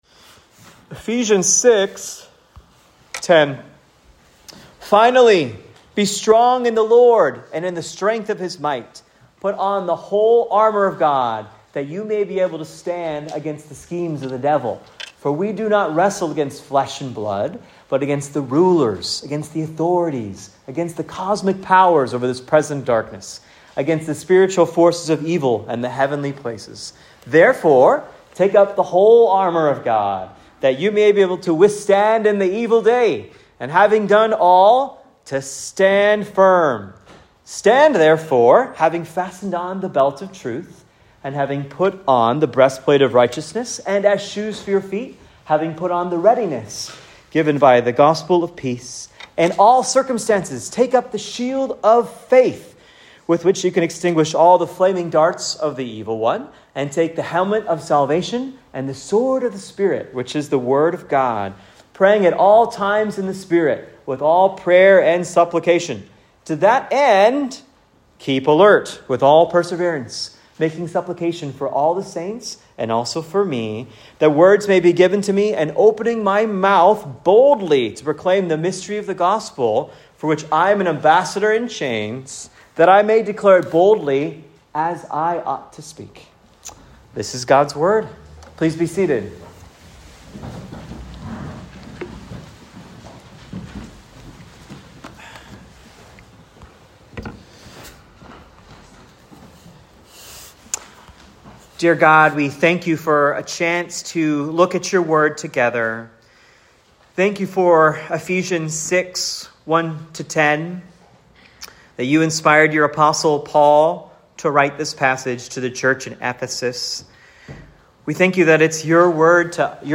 Ephesians 6:10-20 Sermon